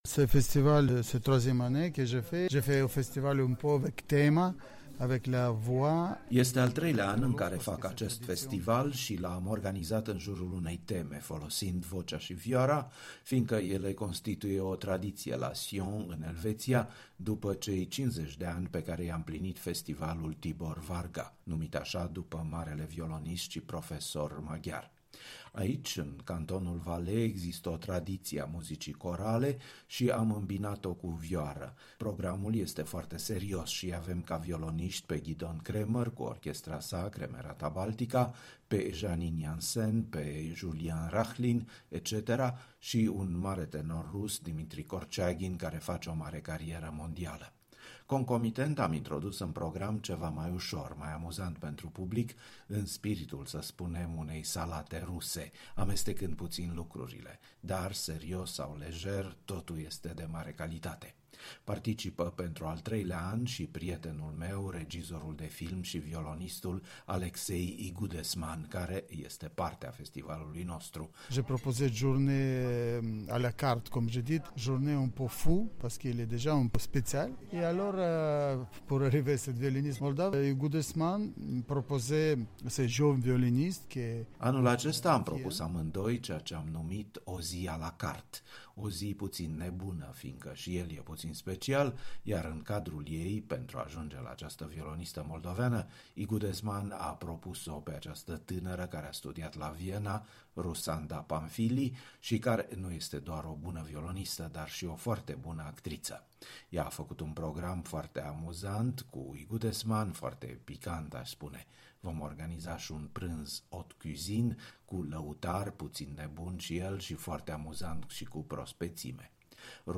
În dialog cu violonistul Pavel Vernikov, directorul-artistic al Festivalului de la Sion (Elveția)